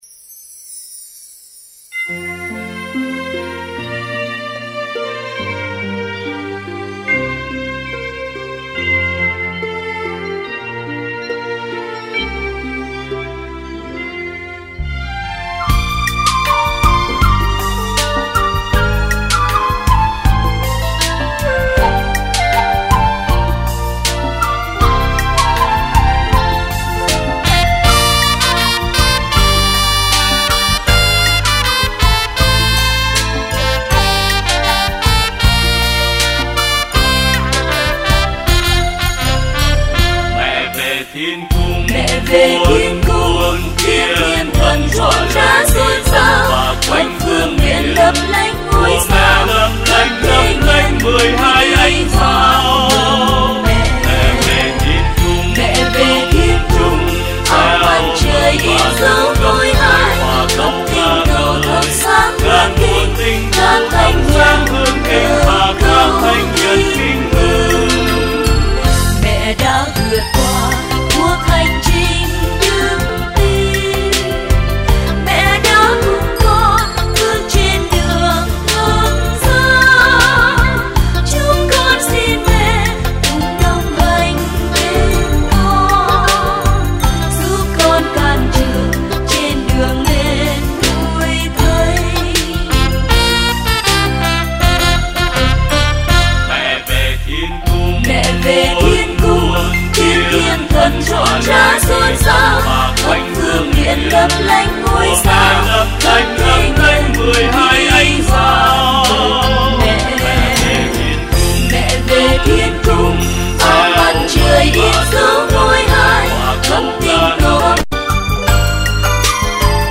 gồm 12 bản Thánh ca